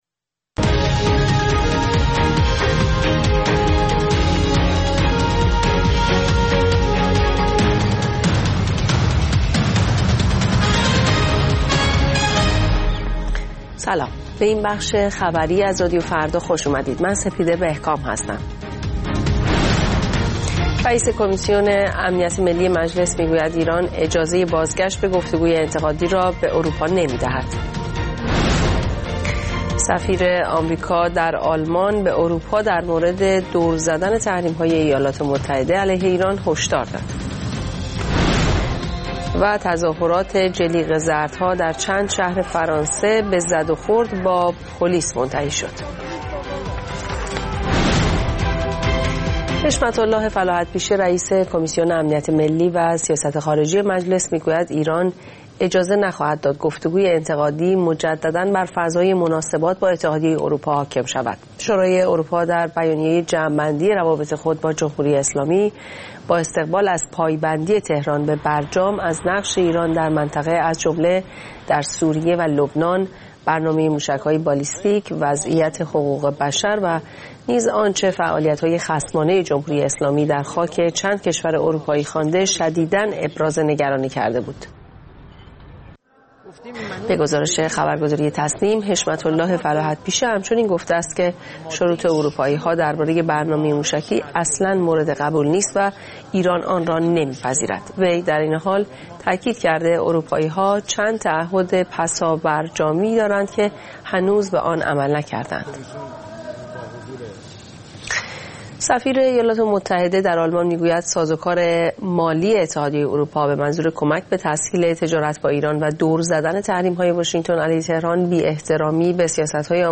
اخبار رادیو فردا، ساعت ۹:۰۰